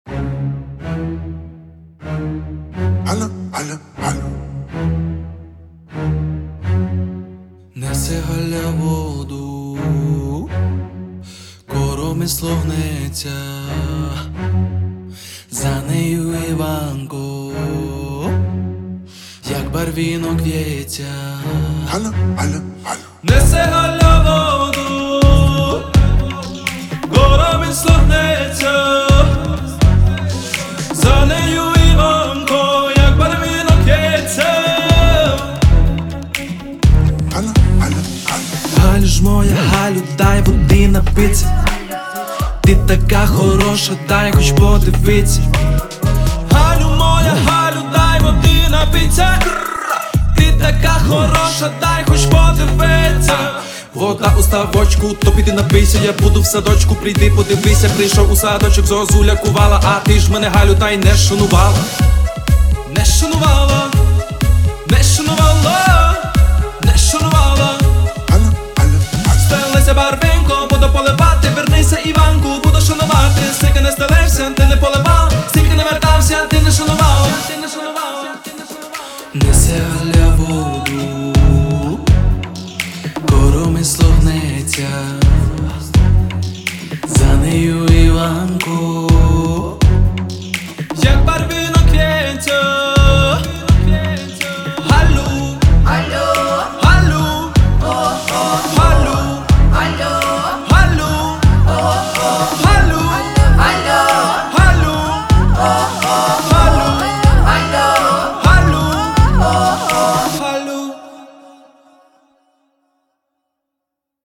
• Жанр: Українська музика